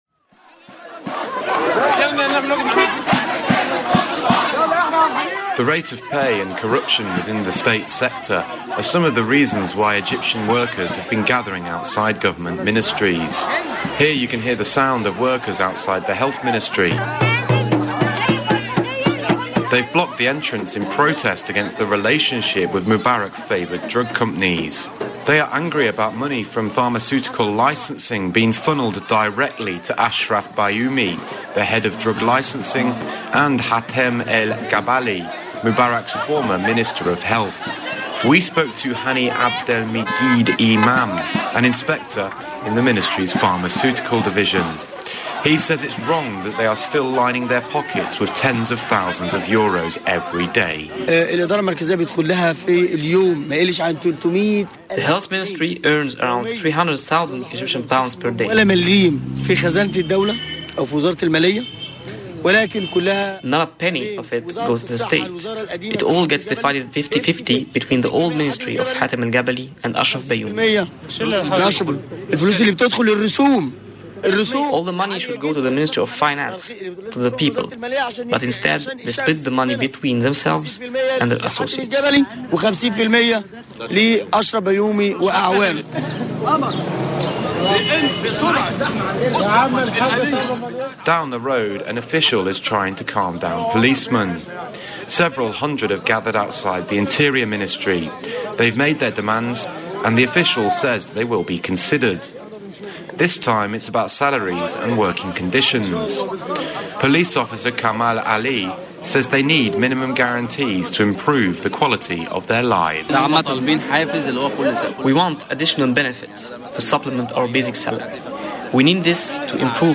Radio Report: public sector strikes